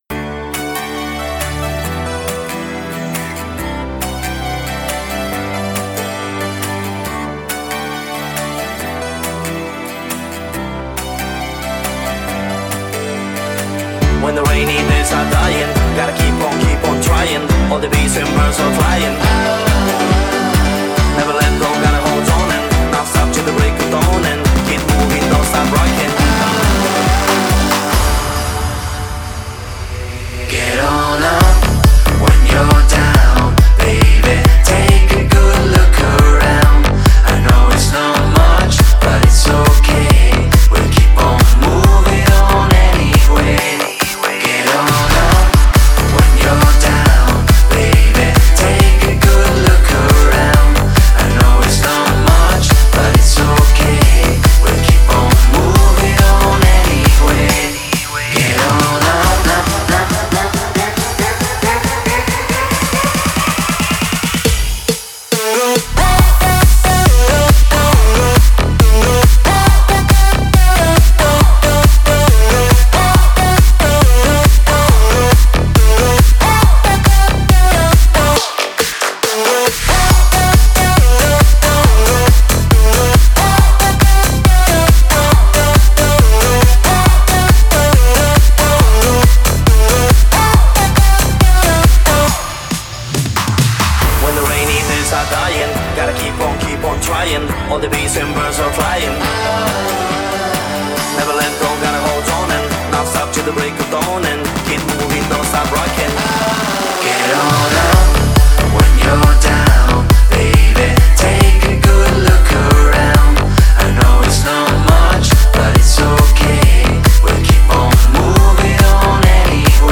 это энергичная танцевальная композиция в жанре евродэнс